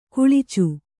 ♪ kuḷicu